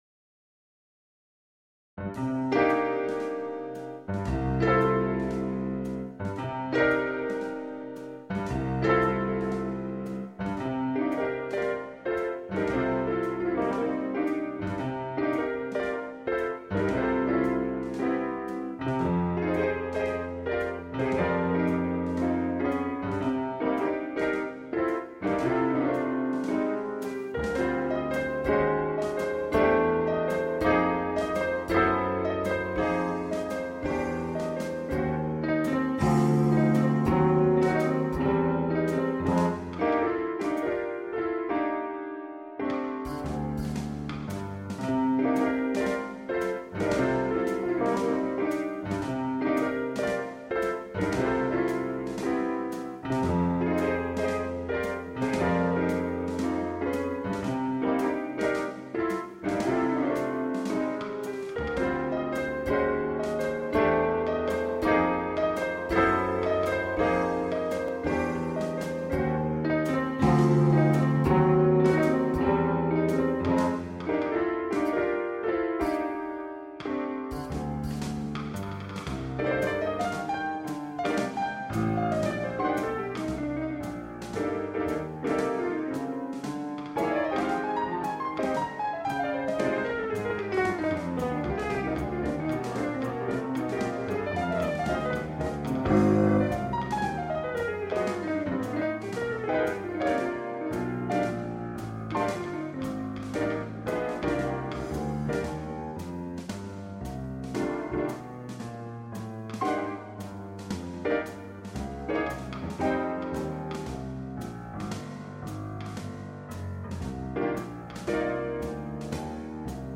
Extensive Kurzweil V.A.S.T.processing by editing new sounds on the instrument gave me some qute new and unique sounds.
This is a recording of a new piano sound: